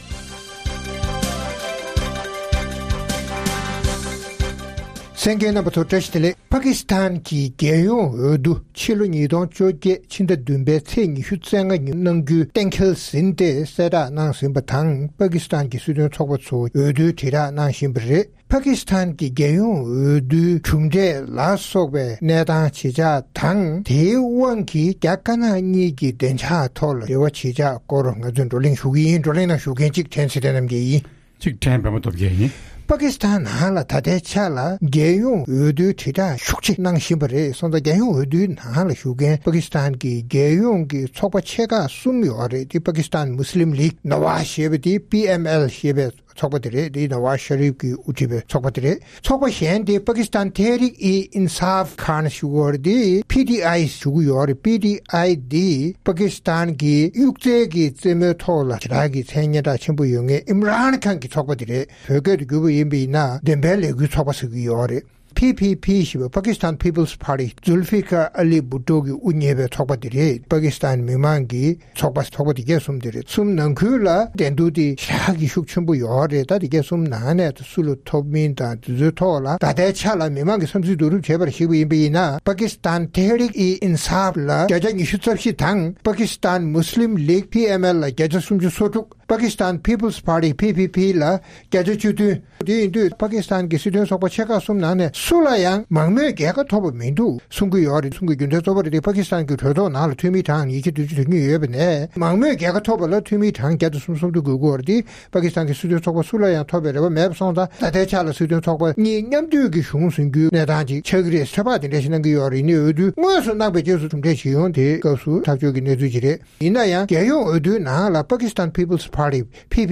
༄༅༎ཐེངས་འདིའི་རྩོམ་སྒྲིག་པའི་གླེང་སྟེགས་ཞེས་པའི་ལེ་ཚན་ནང་། Pakistan གྱི་རྒྱལ་ཡོངས་འོས་བསྡུ་རིང་མིན་ཕྱི་ཟླ་༧་པའི་ཚེས་༢༥་ཉིན་གནང་གཏན་འཁེལ་ཡོད་པ་ལྟར་རྒྱལ་ཡོངས་འོས་བསྡུའི་གྲུབ་འབྲས་ཇི་ཡོང་དང་། དེའི་ཤུགས་རྐྱེན་རྒྱ་གར་ལ་ཇི་ཐེབས། རྒྱ་ནག་གི་ཐེ་གཏོགས་ཡོང་གཞི་ཇི་ཡོད་སོགས་ཀྱི་སྐོར་རྩོམ་སྒྲིག་འགན་འཛིན་རྣམ་པས་བགྲོ་གླེང་གནང་བ་གསན་རོགས་གནང་།།